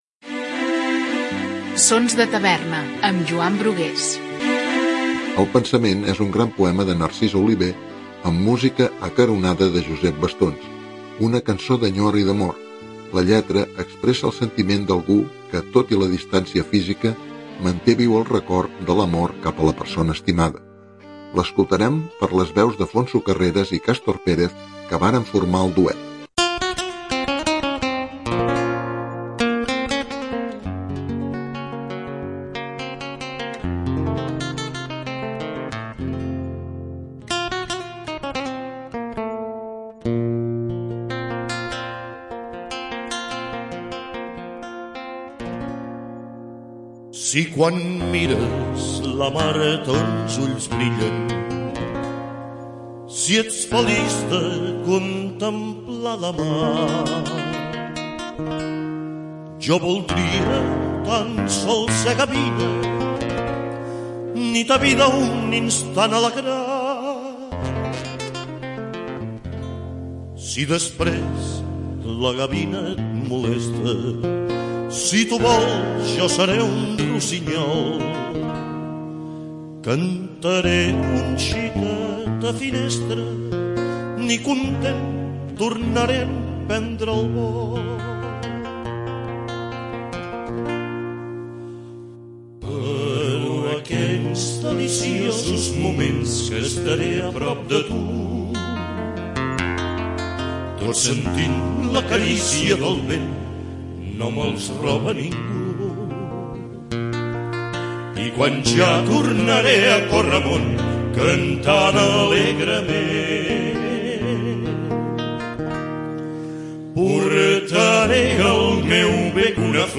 amb una música acaronant
Una cançó d’enyor i amor.